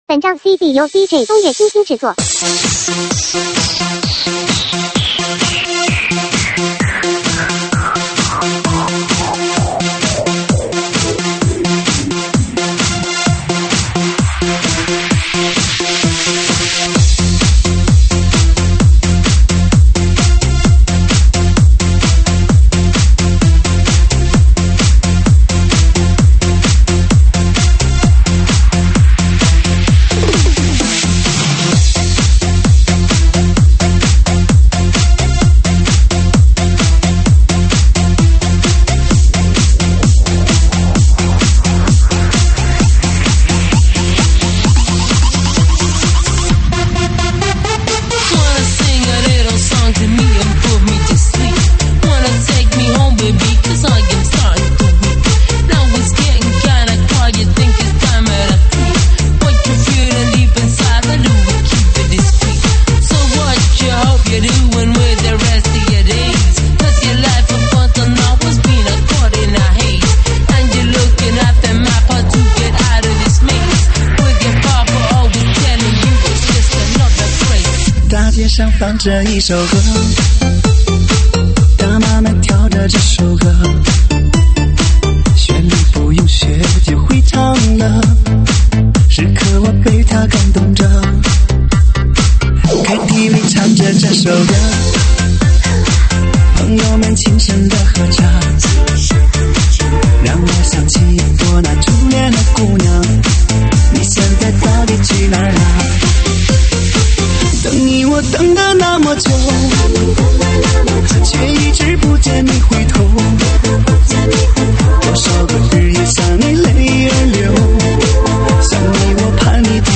伤感情歌